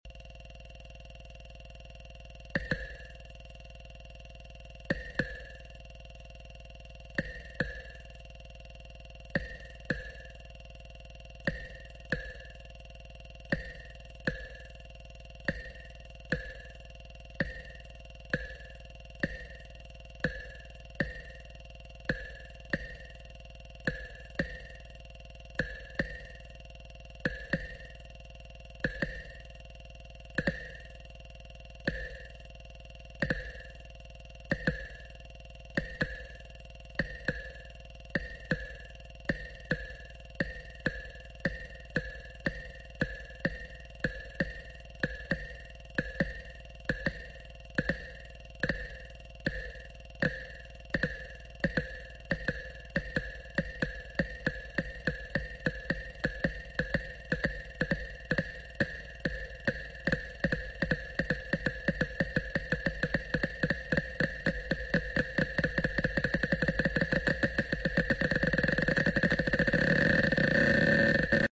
Squares colliding until they get too fast ⏩